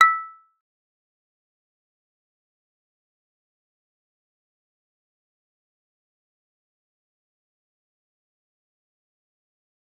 G_Kalimba-E6-f.wav